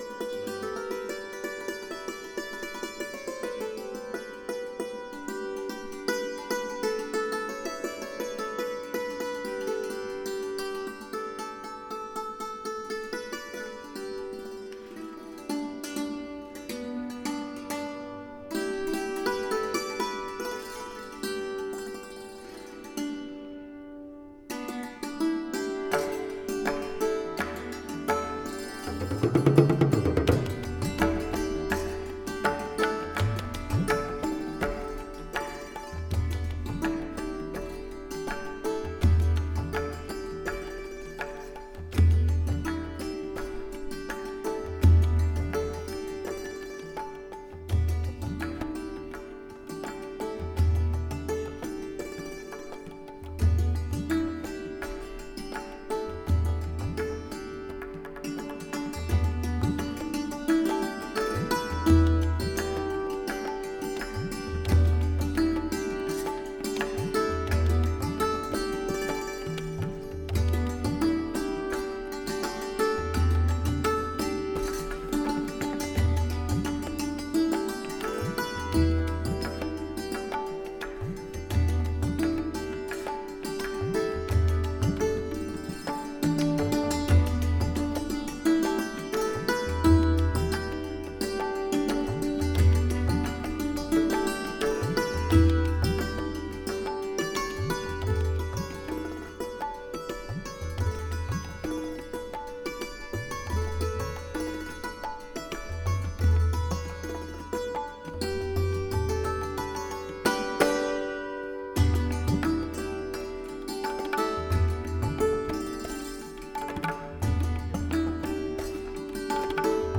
这张唱片，2000年9月13日录制于意大利都灵“Festival Settembre Musica”的现场。